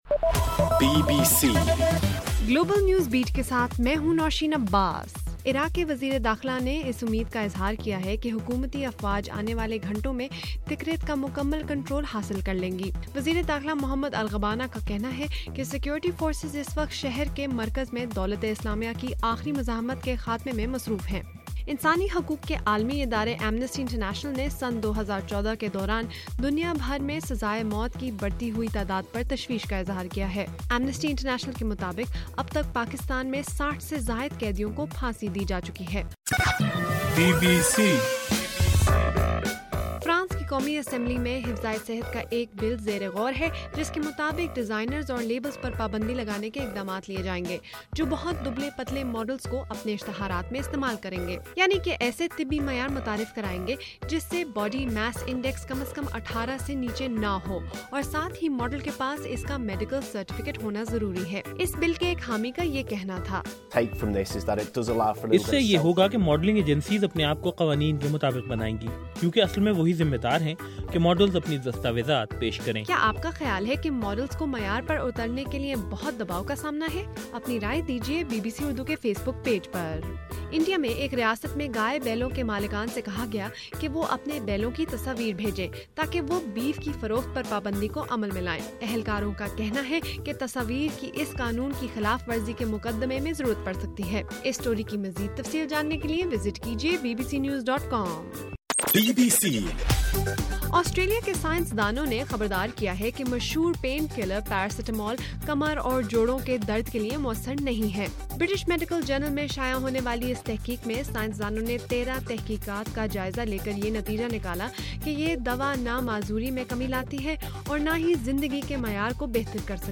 اپریل 1: رات 12 بجے کا گلوبل نیوز بیٹ بُلیٹن